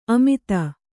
♪ amita